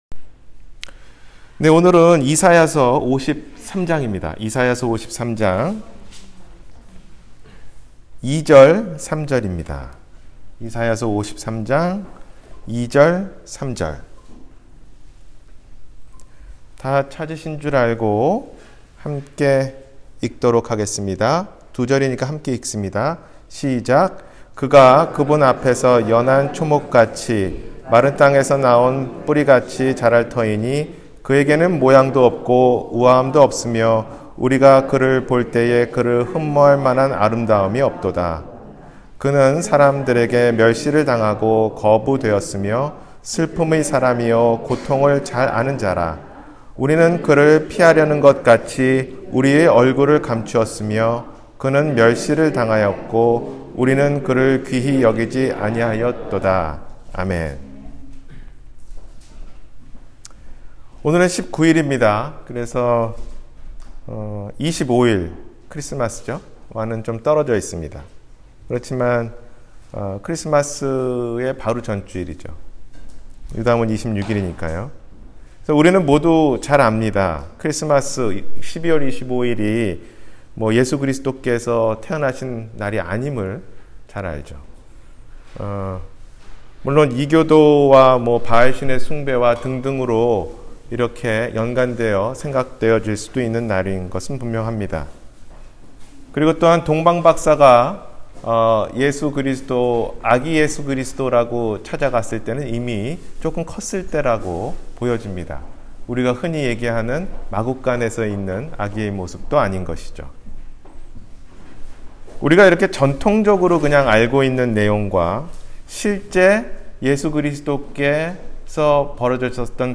놀랍지만 놀랍지 않은 일 – 주일설교 – 갈보리사랑침례교회